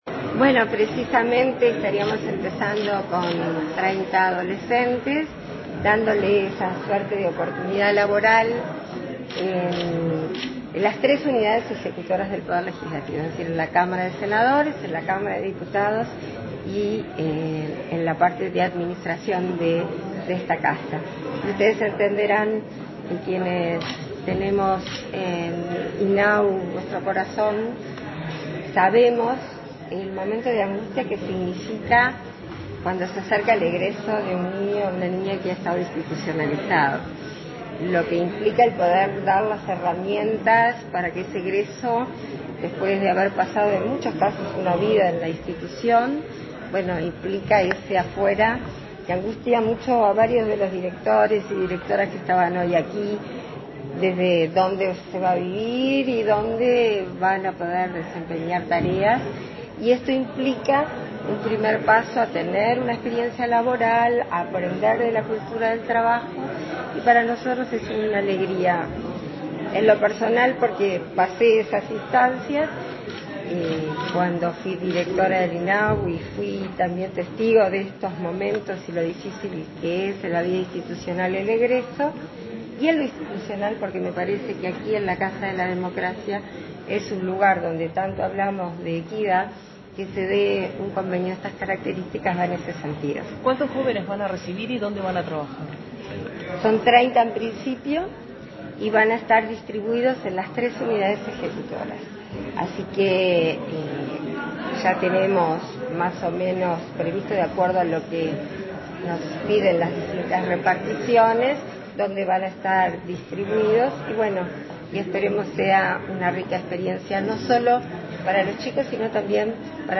Declaraciones a la prensa de la vicepresidenta de la República, Beatriz Argimón